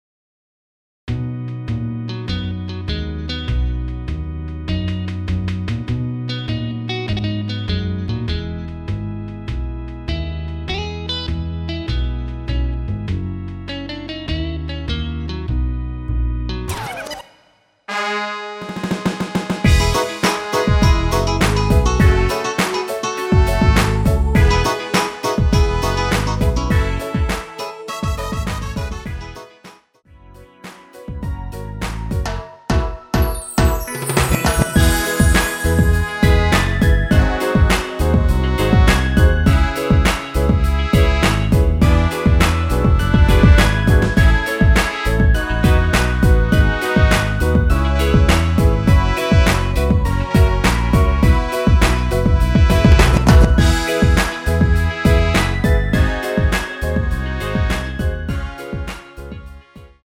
원키에서(-2)내린 MR입니다.
◈ 곡명 옆 (-1)은 반음 내림, (+1)은 반음 올림 입니다.
앞부분30초, 뒷부분30초씩 편집해서 올려 드리고 있습니다.